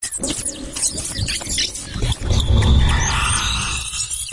描述：录音：我是用Native Instruments的Reaktor对各种样本进行了大量的处理，创造出了声音设计
Tag: 苛刻 工业 噪音 噪音大 加工 电子 声音设计